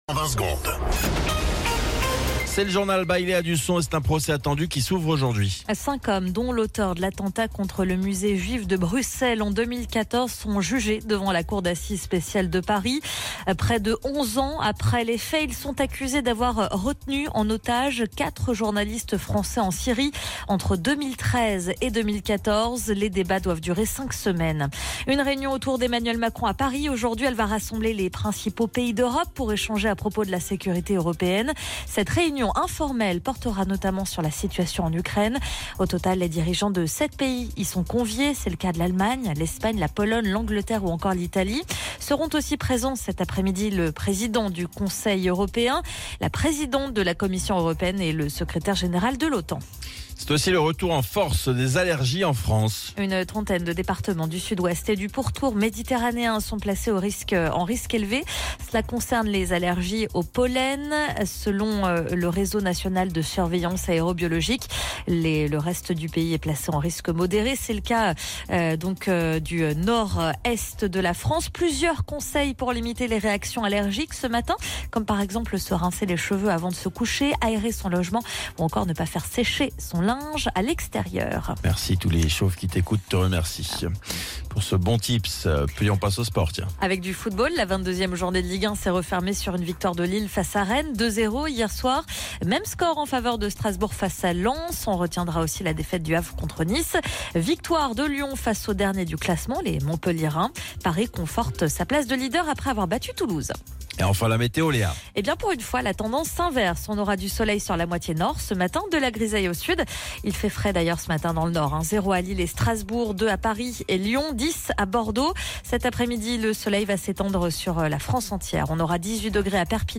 Flash Info National 17 Février 2025 Du 17/02/2025 à 07h10 .